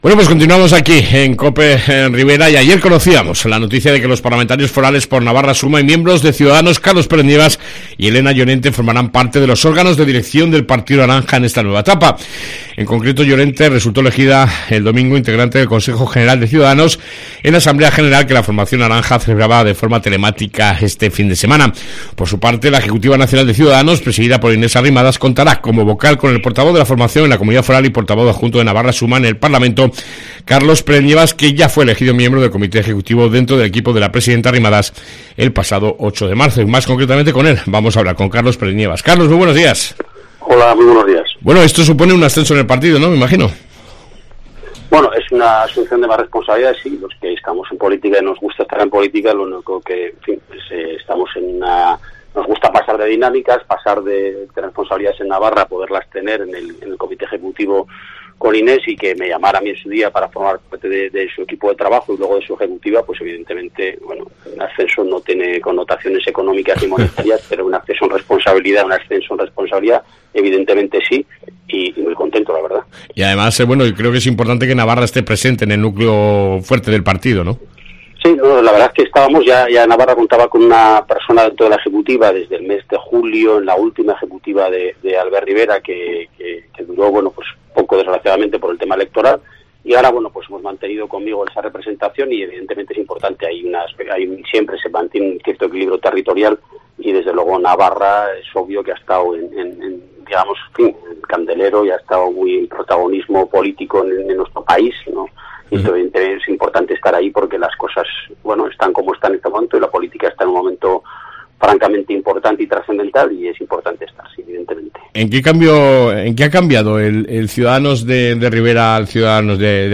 AUDIO: Entrevista con el Portavoz en Navarra de Ciudadanos , Carlos Pérez-Nievas